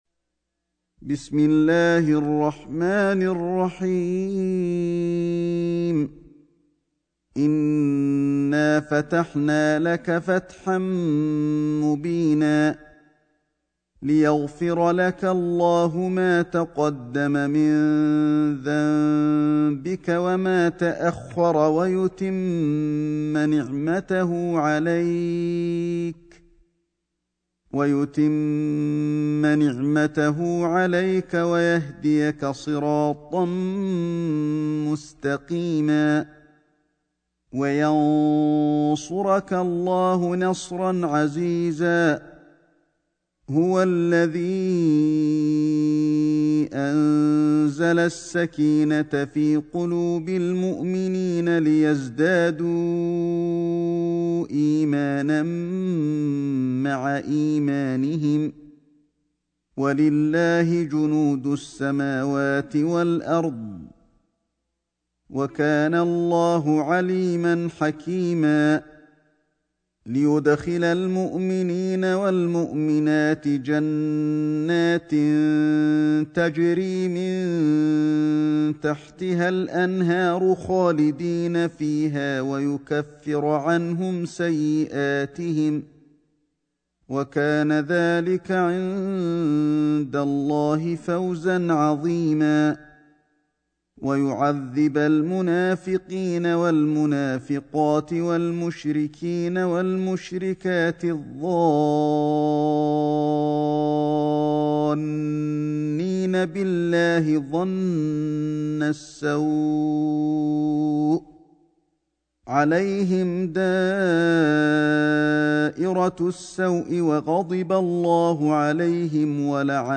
سورة الفتح > مصحف الشيخ علي الحذيفي ( رواية شعبة عن عاصم ) > المصحف - تلاوات الحرمين